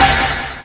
superjumpgood.wav